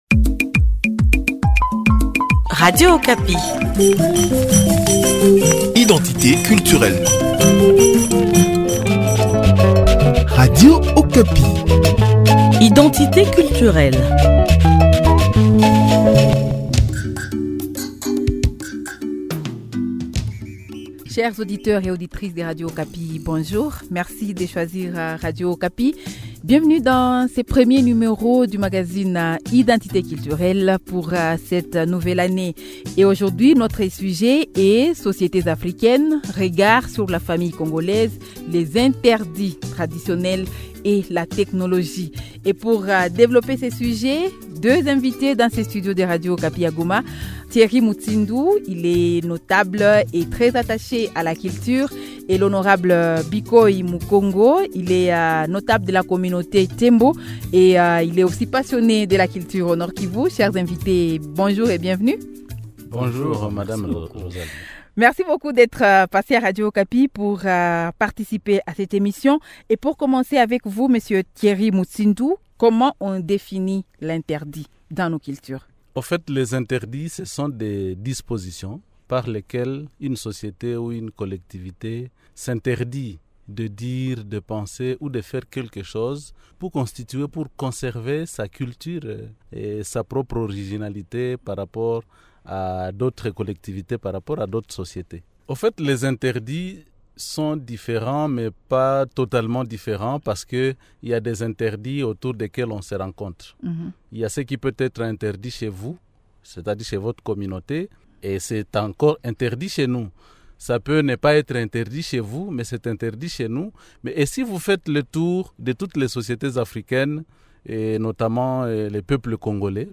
Pour en débattre, nous recevons deux invités :